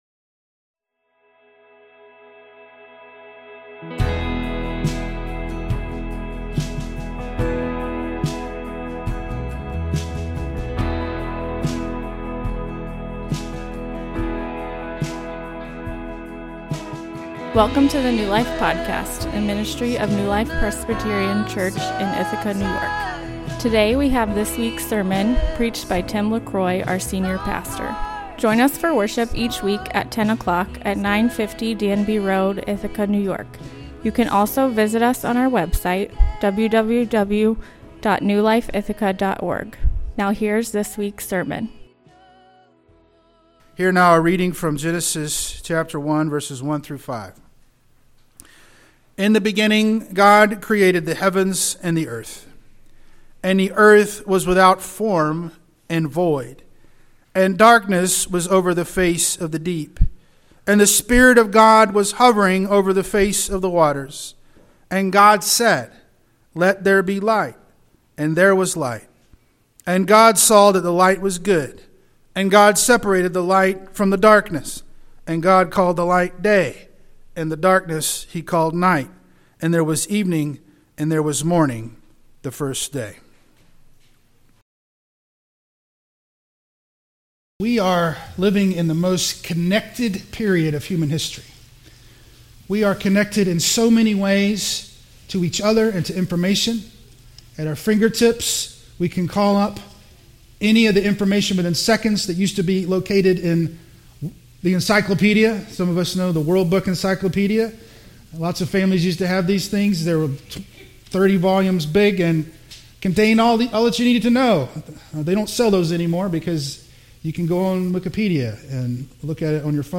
A loneliness epidemic is plaguing our society. Why is that and how do we fix it? The answer to both is found in the Triune God. A sermon for Trinity Sunday.